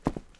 Footstep Concrete Walking 1_02.wav